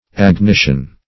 Search Result for " agnition" : The Collaborative International Dictionary of English v.0.48: Agnition \Ag*ni"tion\ ([a^]g*n[i^]sh"[u^]n), n. [L. agnitio, fr. agnoscere.